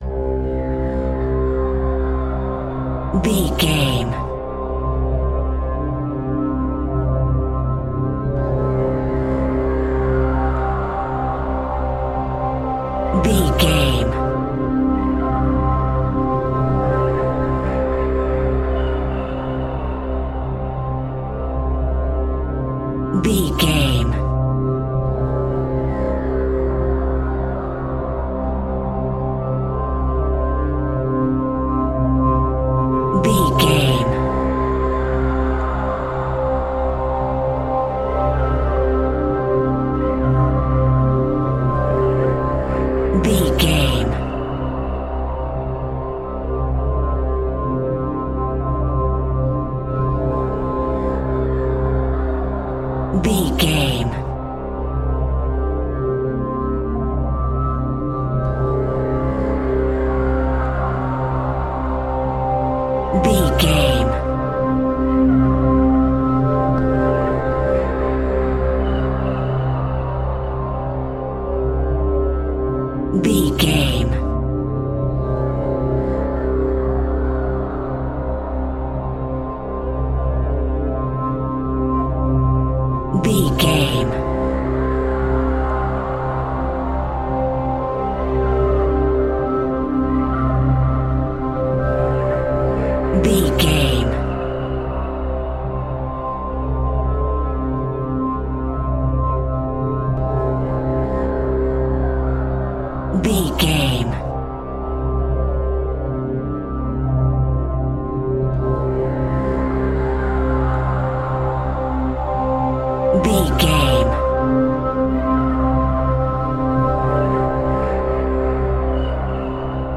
Phrygian
Slow
ambient
indian sitar
ethnic synths